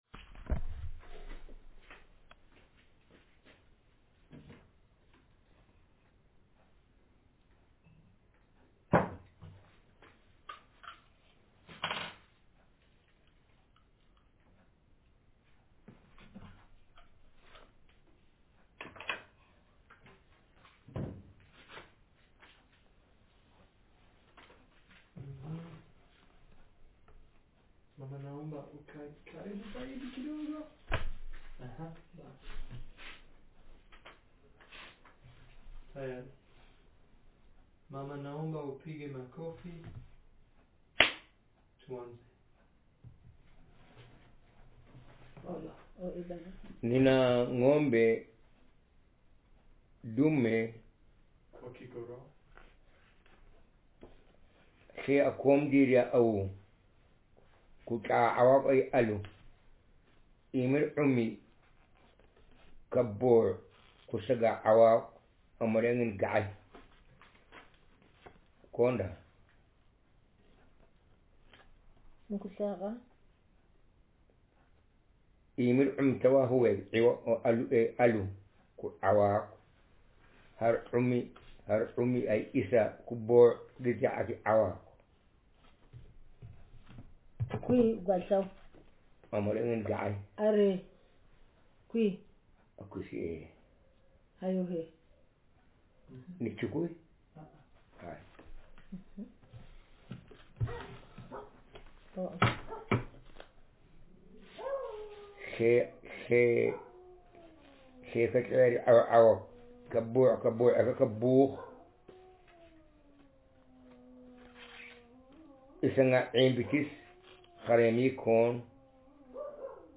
Speaker sexm/f
Text genreconversation